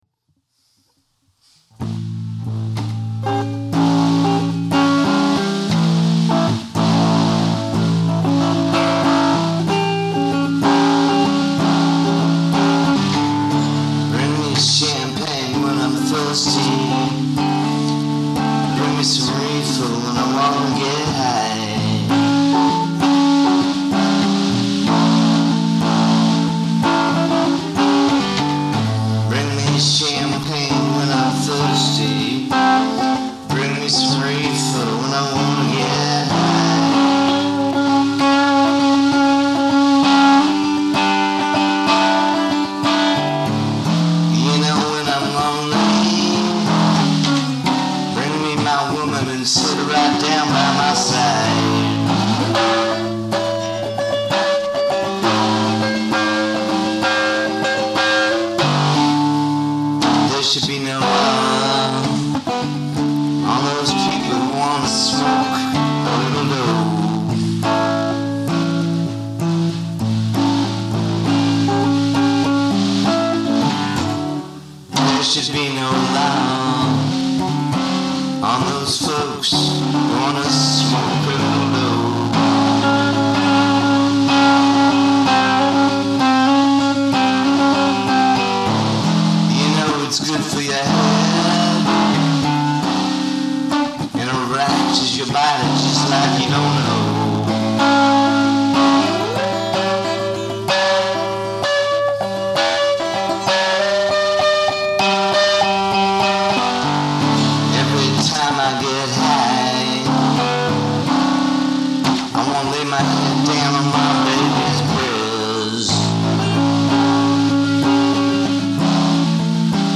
I do like the retro feel though.